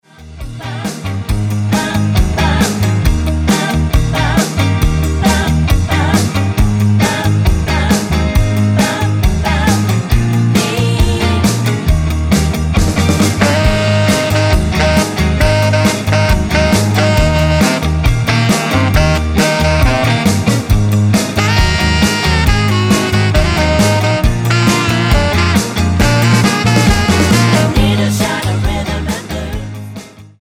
Tonart:Eb mit Chor